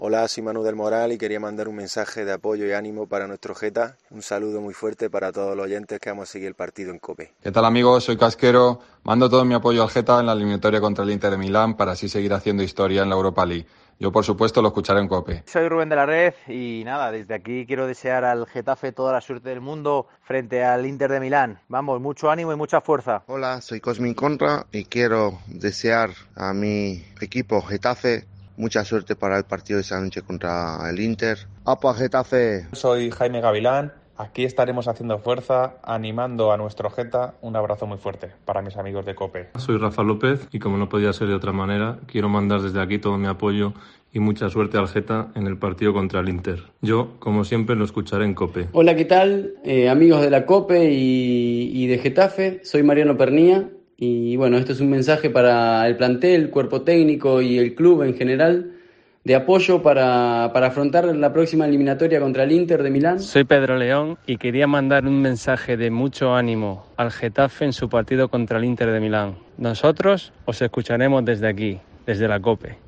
Cosmin Contra, Javi Casquero, Manu del Moral, Jaime Gavilán, Rubén de la Red, Rafa López, Mariano Pernía y Pedro León han pasado por los micrófonos de COPE para apoyar al Getafe.